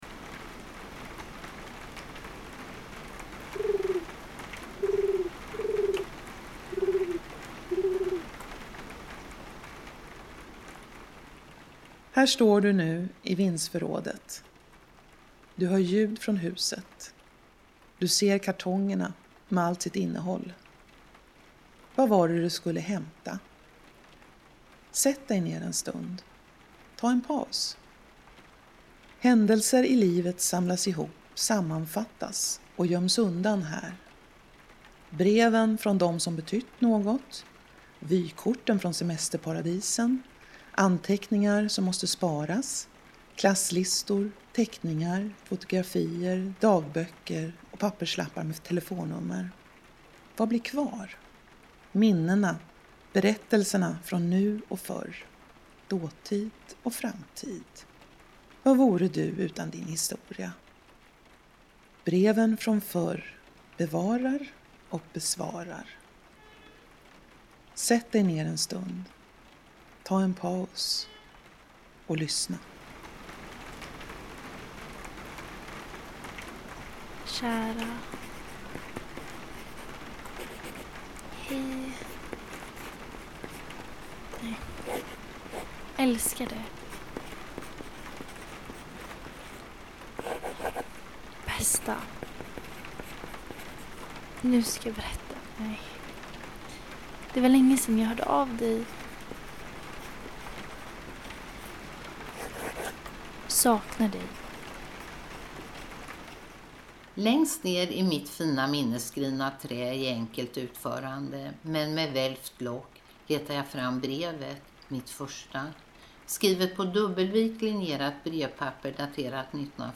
Horspel_Vindsforrad.mp3